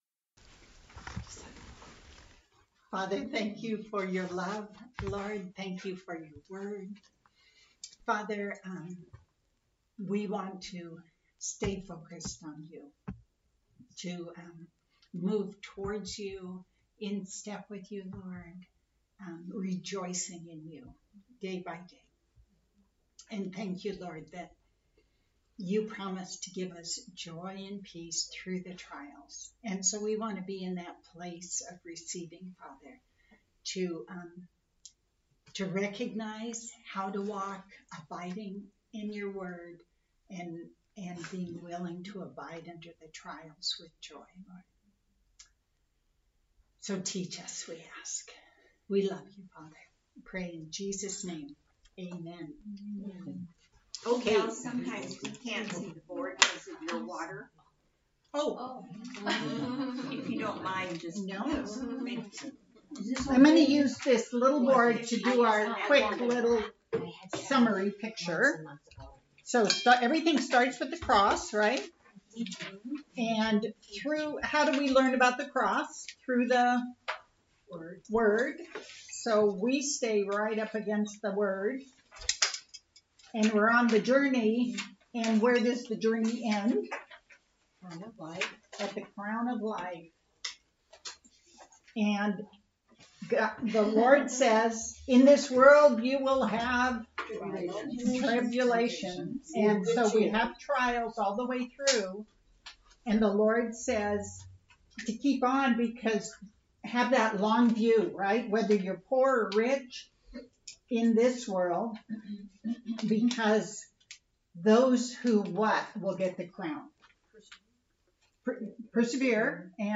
A message from the series "James W."